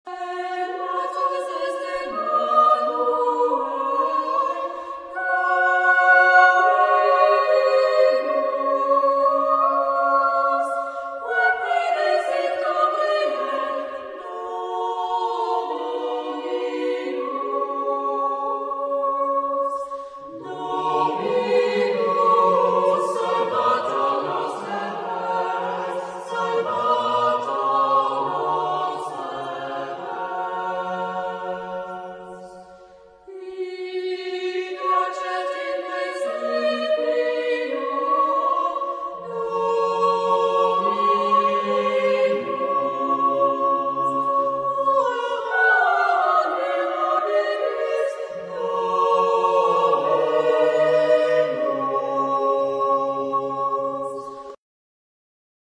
Genre-Style-Form: Christmas song ; Sacred
Type of Choir: SMA OR SSA  (3 women voices )
Tonality: F major